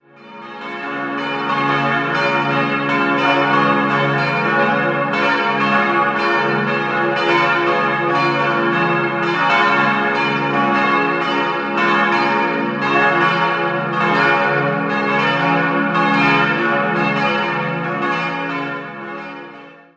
Laub, Pfarrkirche Unsere liebe Frau zur guten Heimkehr Laub gehört zur Gemeinde Zeitlarn im nördlichen Landkreis Regensburg und liegt direkt am Fluss Regen. Die geräumige, moderne Pfarrkirche wurde zwischen 1964 und 1966 errichtet. 4-stimmiges Geläut: des'-f'-as'-b' Die Glocken sind Maria, Josef, Gabriel und Michael geweiht und wurden 1965 von Rudolf Perner in Passau gegossen.